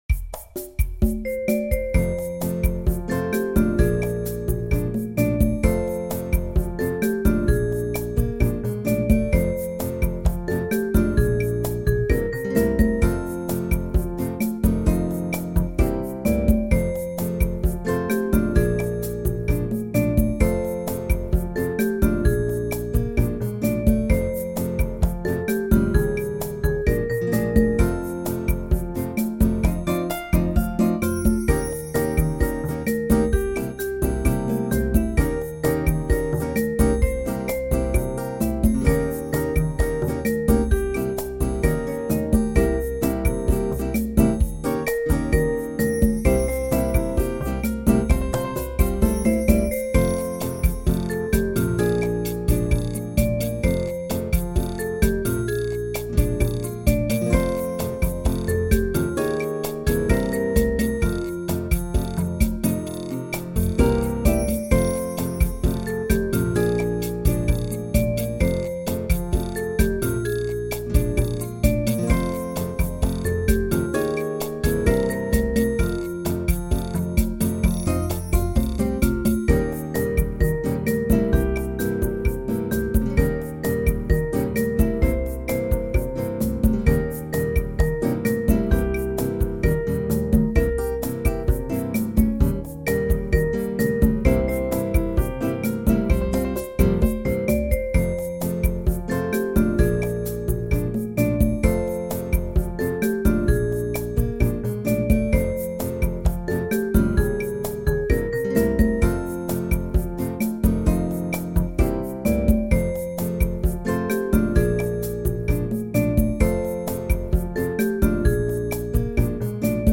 PC演奏
ボサノバふうにしてみたらどんな感じ？